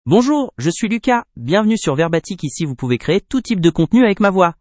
LucasMale French AI voice
Lucas is a male AI voice for French (France).
Voice sample
Listen to Lucas's male French voice.
Male
Lucas delivers clear pronunciation with authentic France French intonation, making your content sound professionally produced.